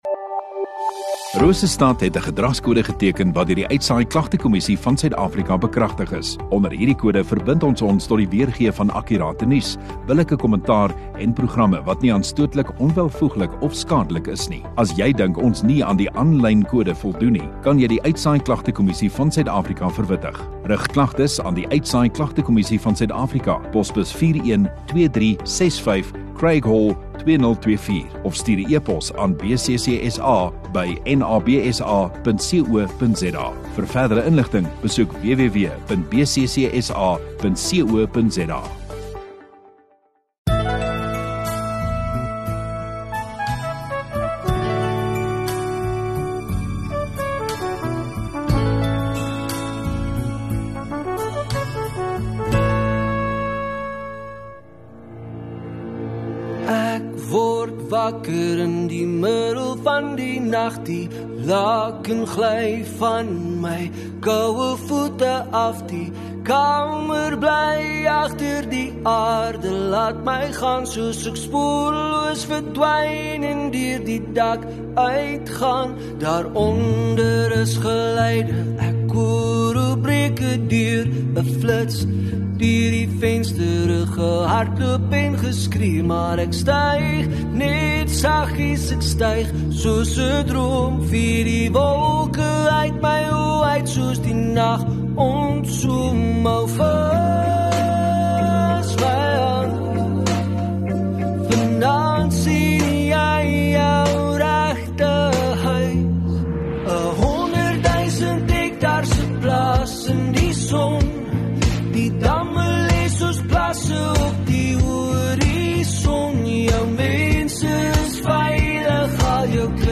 21 Jul Sondagaand Erediens